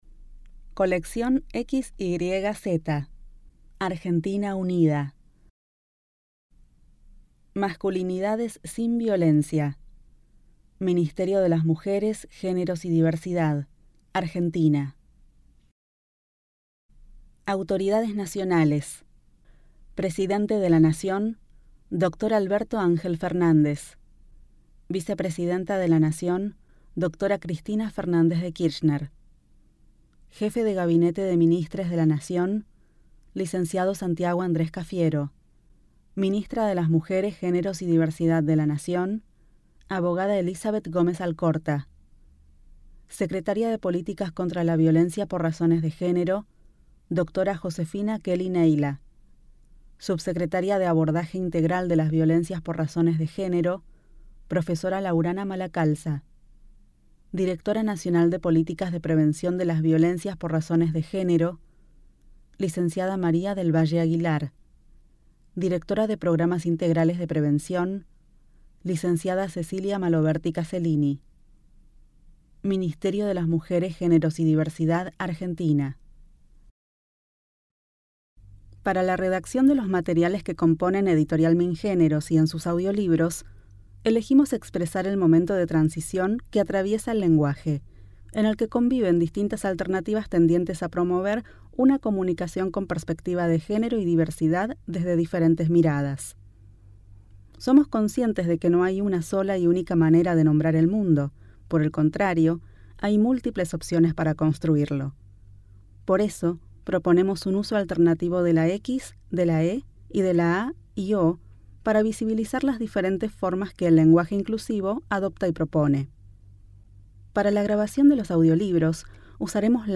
Masculinidades - Audiolibro Completof85c.mp3